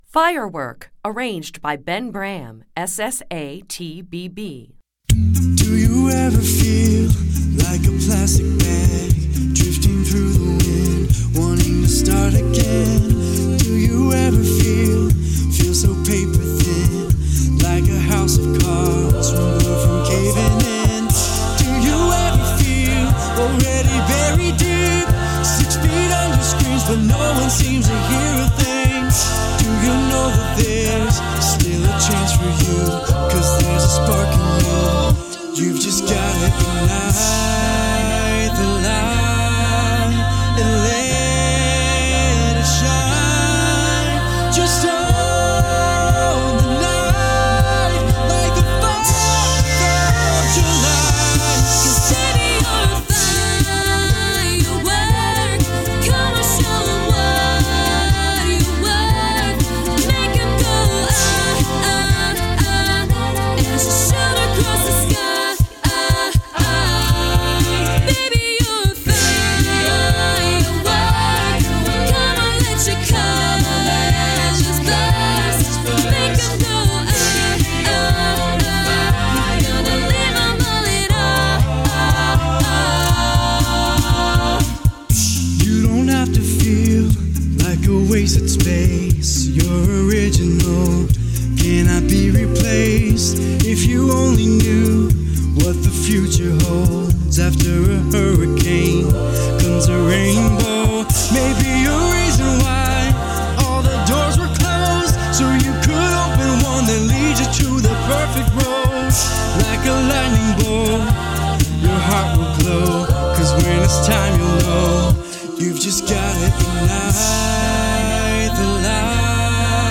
Voicing: SSATBB